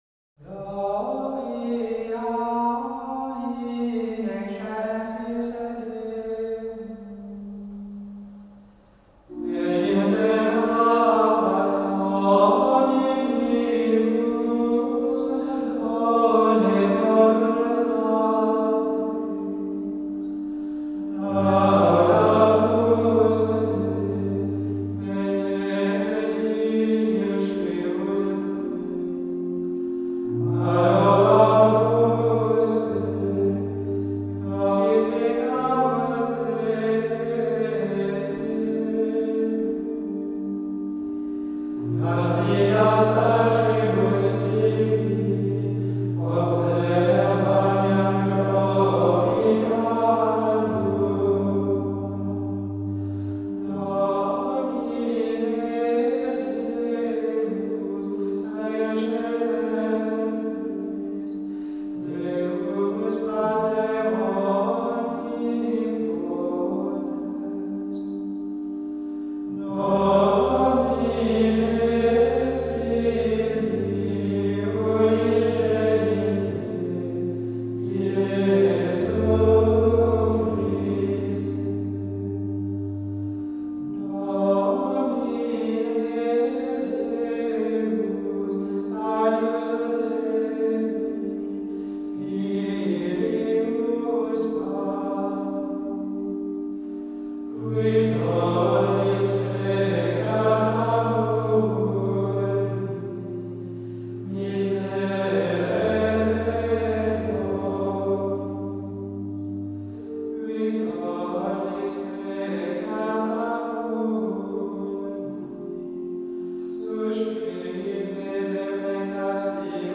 canto gregoriano, cantos de navidad 15 - coros gregorianos455.mp3